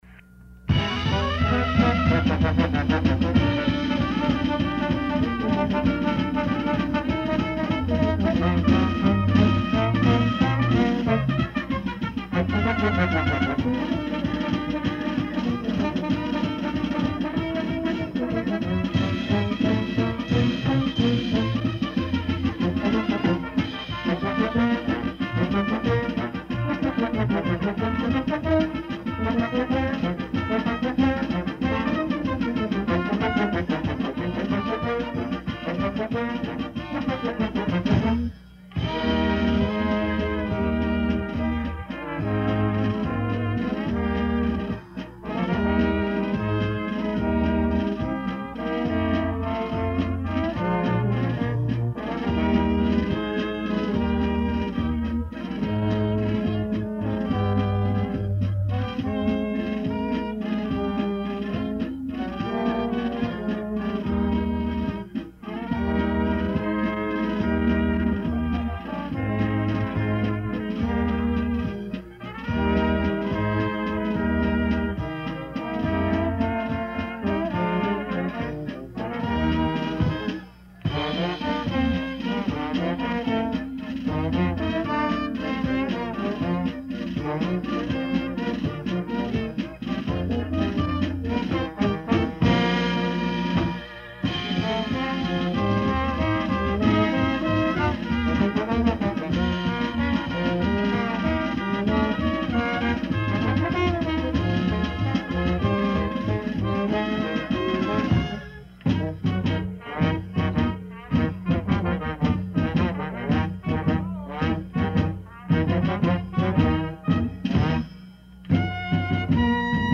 Here is a recording of the complete 1961 Clyde Beatty Cole Bros. performance that was recorded in Orlando, Florida.